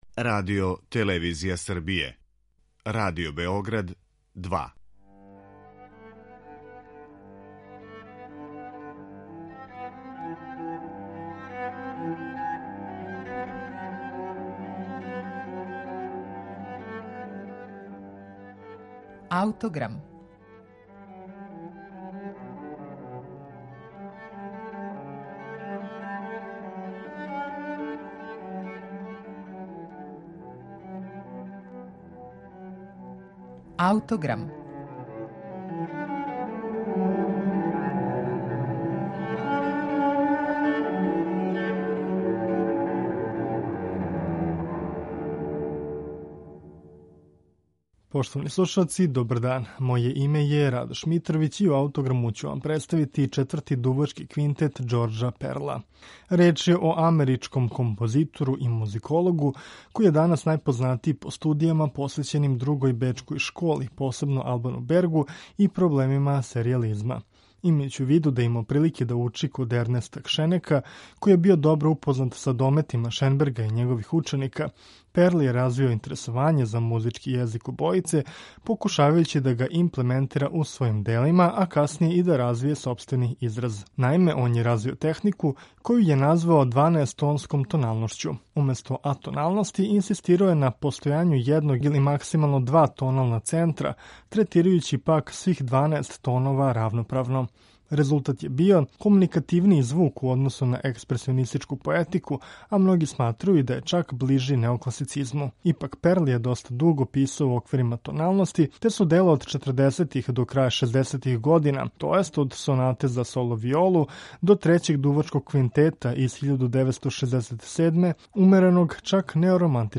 дувачког квартета